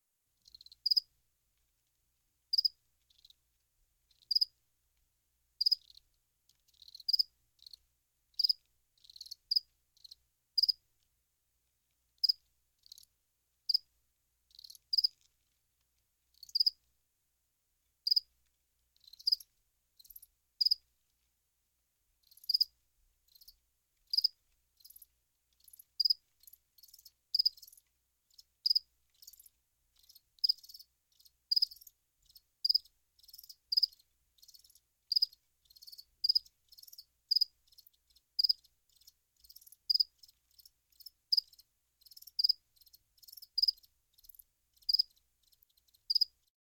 insectnight_4.ogg